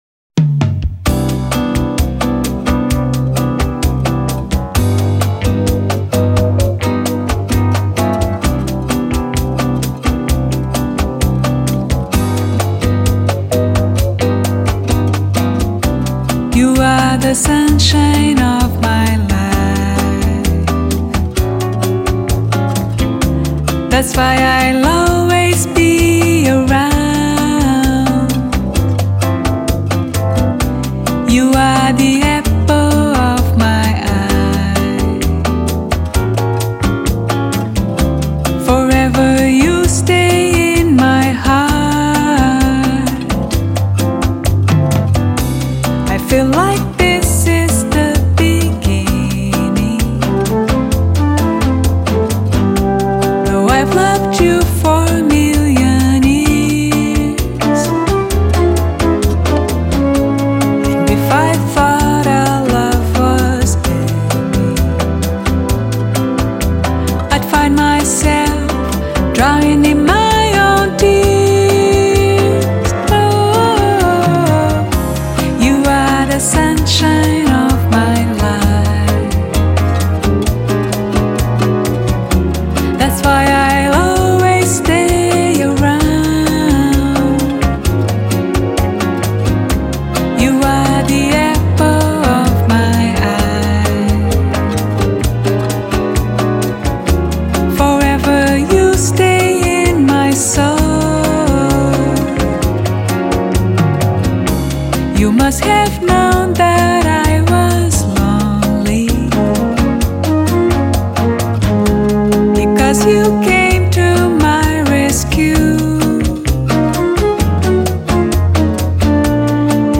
音樂類型 : 爵士樂  Bossa Nova[center]